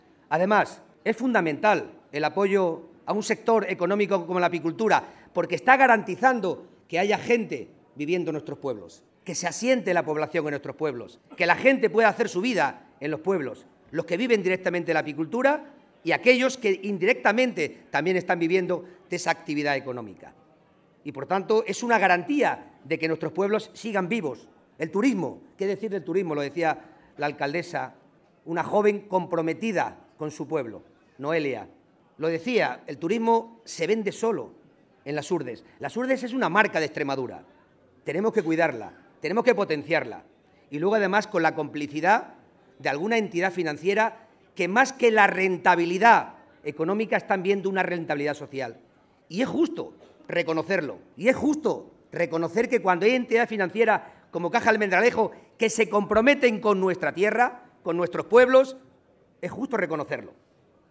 El presidente de la Diputación de Cáceres ha intervenido en el acto inaugural de la IX Feria de Apicultura y Turismo de Las Hurdes, donde ha lanzado un mensaje claro “de diálogo, de concordia, de colaboración institucional, el mensaje de que todos y todas somos importantes para hacer cada vez más grande esta tierra”
CORTES DE VOZ